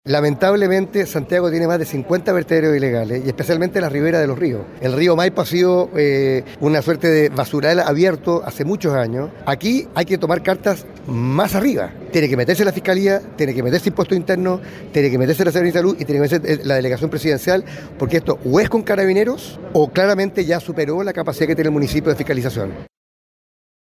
Consultado por Radio Bío Bío, Orrego pidió la intervención de la Fiscalía, Impuestos Internos, seremi de Salud y delegación presidencial, para intervenir el lugar. Y con ello, desarticular a los grupos de personas que realizan este tipo infracciones, a los que denominó como “mafias” que operan en la zona.